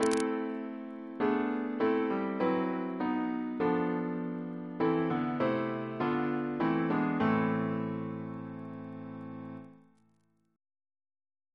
Single chant in A minor Composer: Chris Biemesderfer (b.1958)